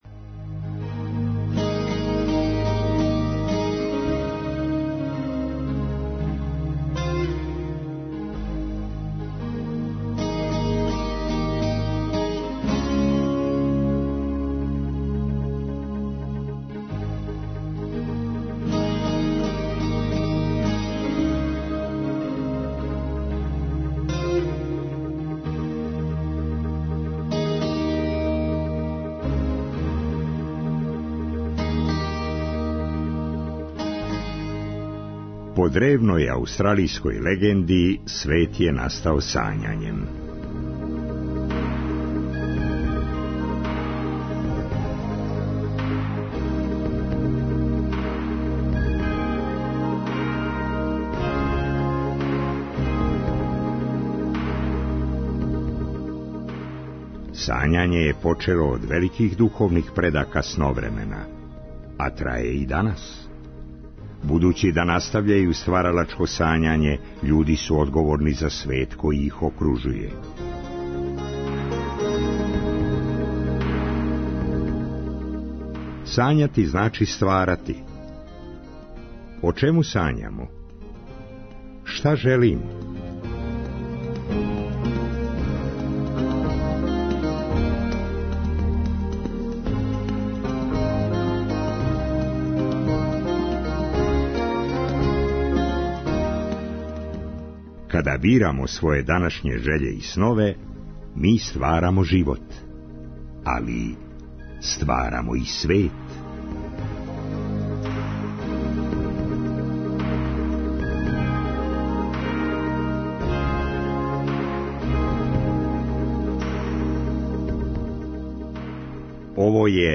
Ноћ за музику и приче. Путујемо кроз Сновреме.